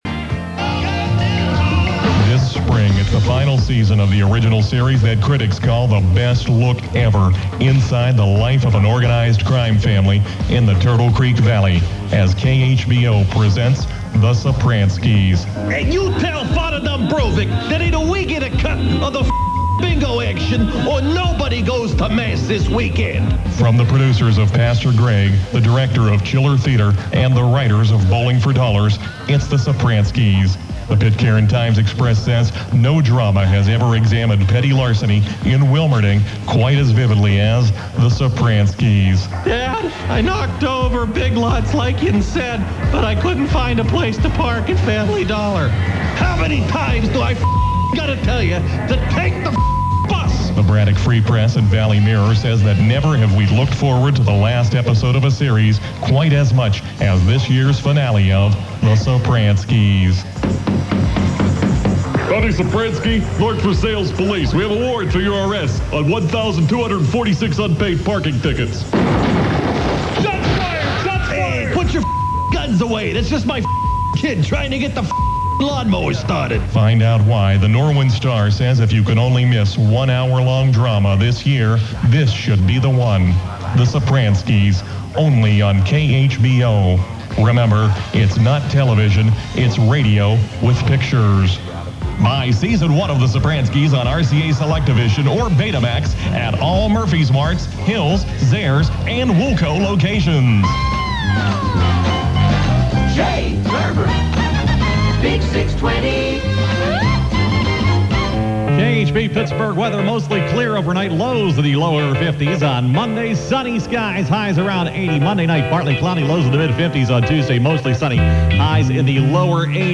alleged humor from my alleged radio show.
This is a clip from Sunday night's travesty broadcast, but the bit is actually a rerun from a couple of months ago.